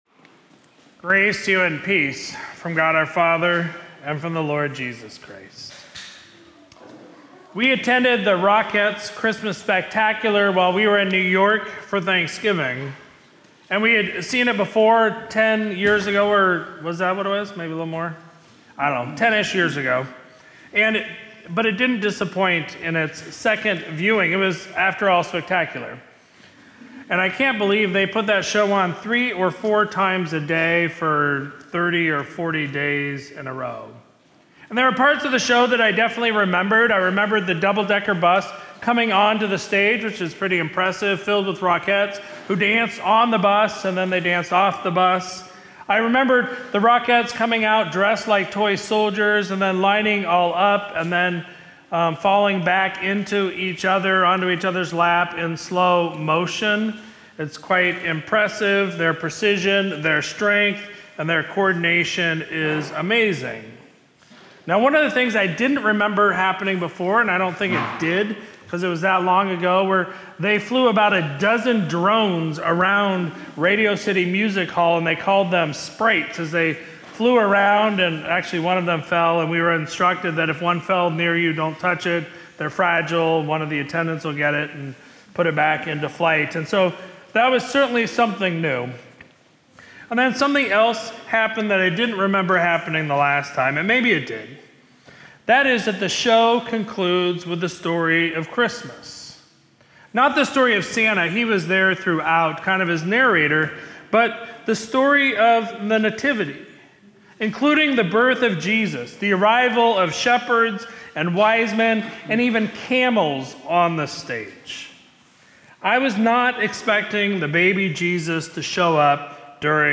Sermon from Sunday, December 22, 2024